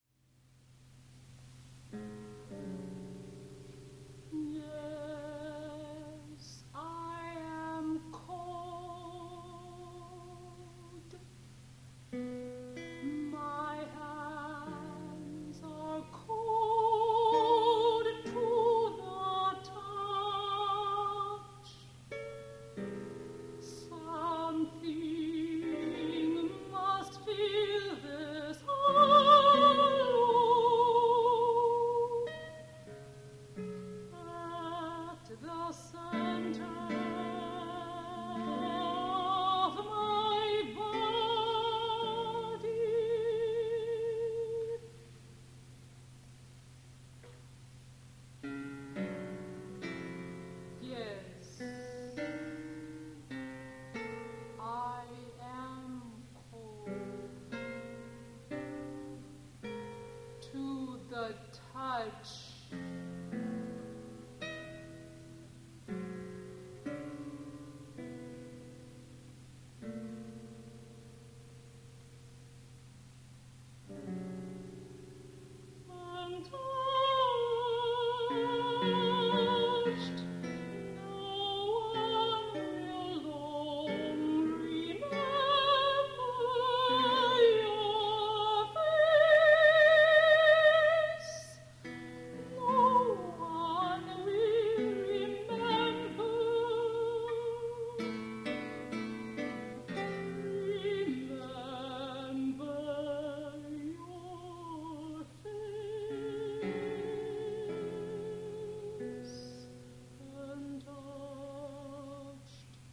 soprano, guitar        Denton, Texas  1983